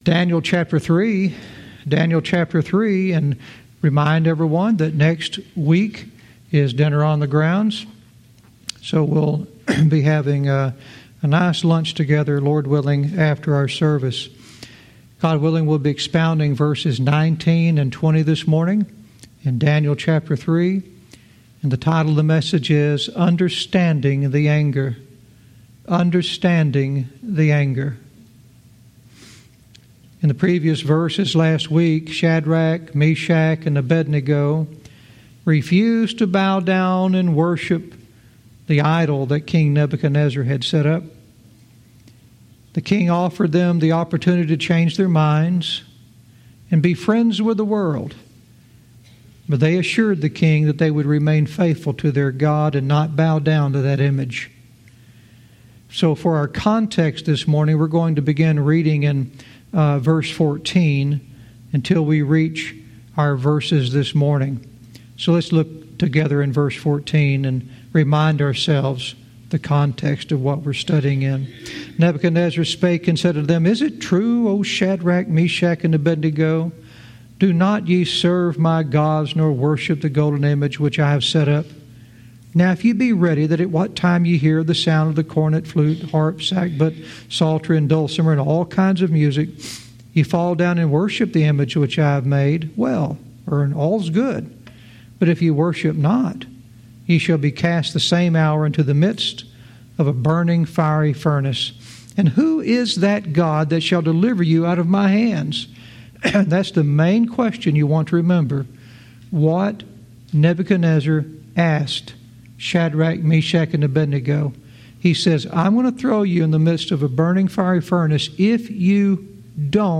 Verse by verse teaching - Daniel 3:19-20 "Understanding the Anger"